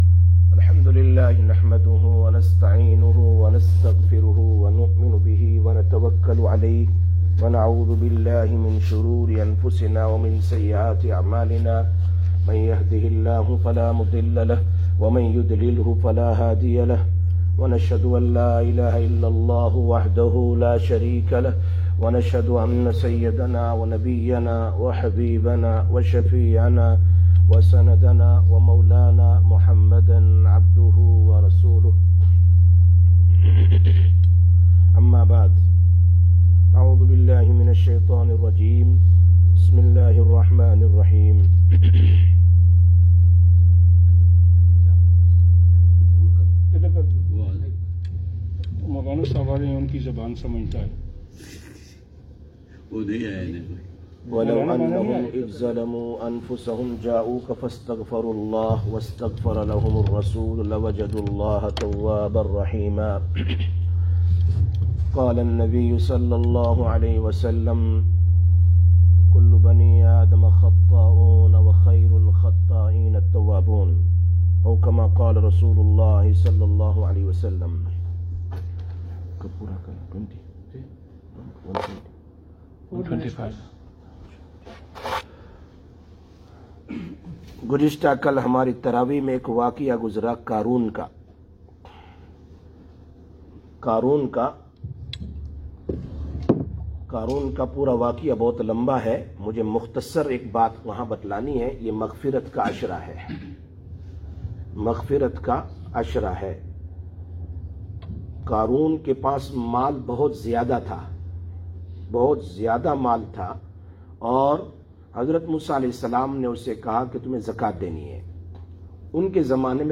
30/04/2021 Masjid Darul Mahmood Jummah Bayaan